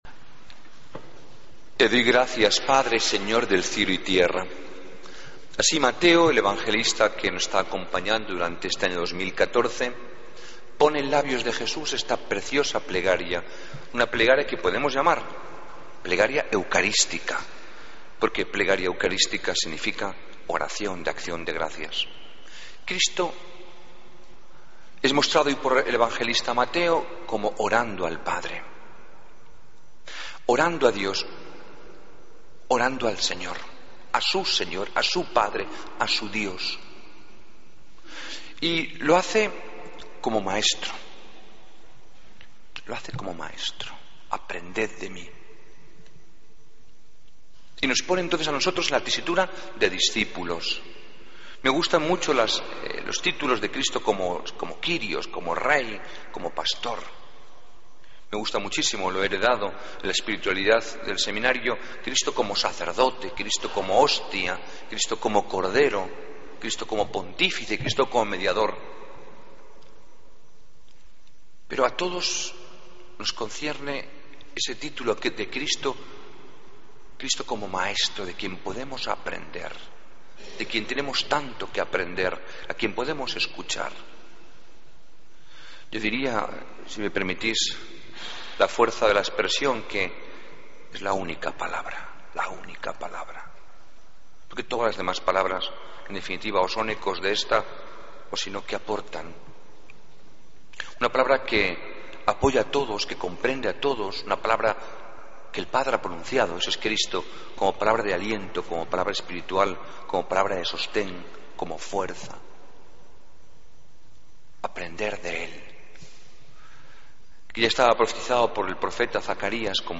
Homilía del Domingo 6 de Julio de 2014